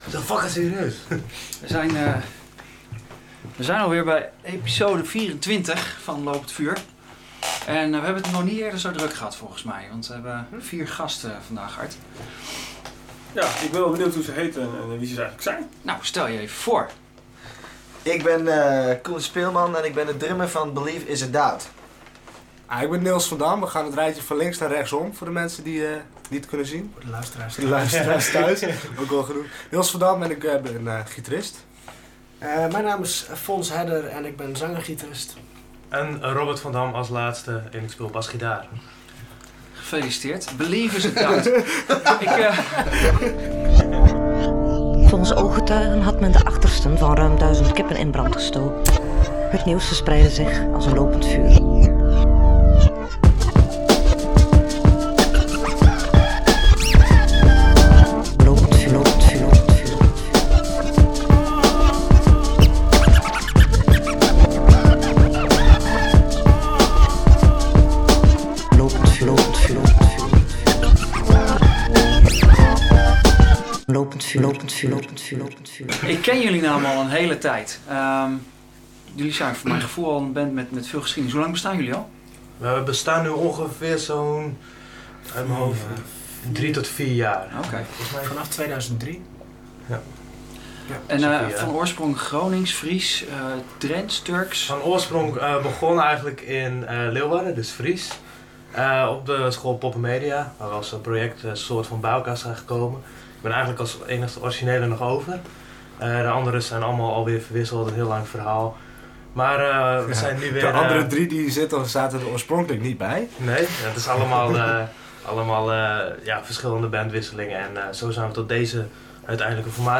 Weliswaar semi-akoestisch maar niet minder opzwepend.